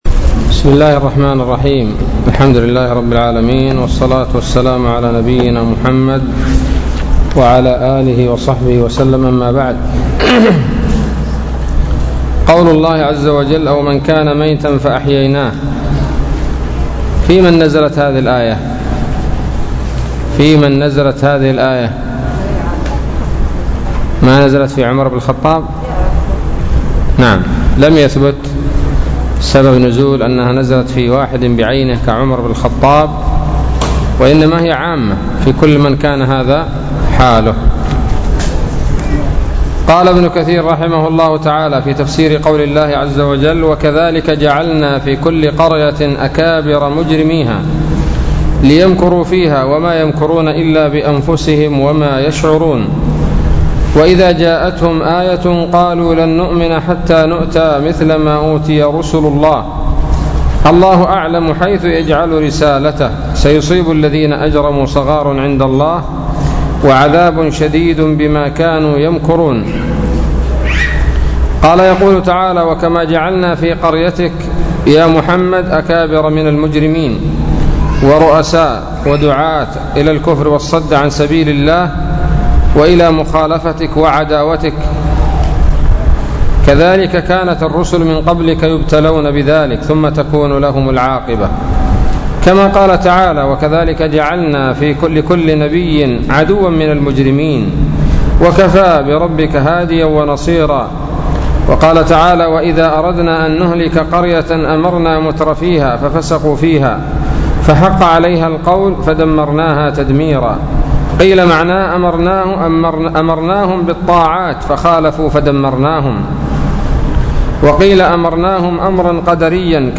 الدرس السادس والأربعون من سورة الأنعام من تفسير ابن كثير رحمه الله تعالى